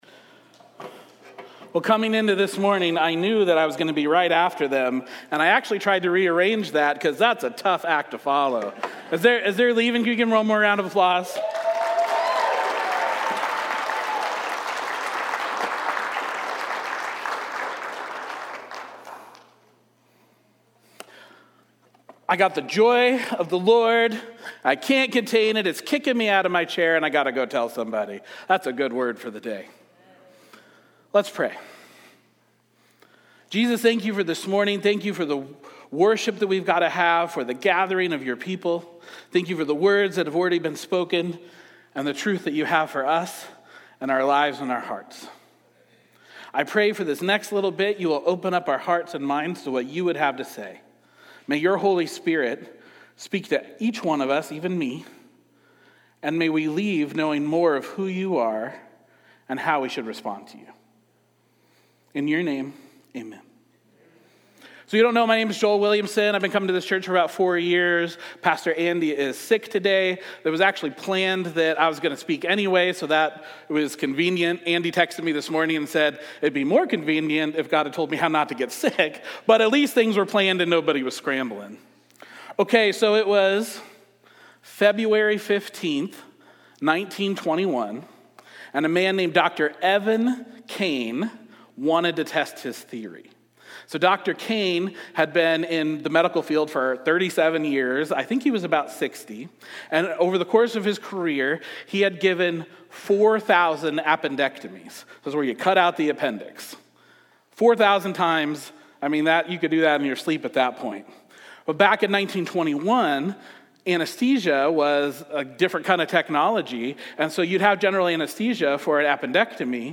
Sermons | White Rock Fellowship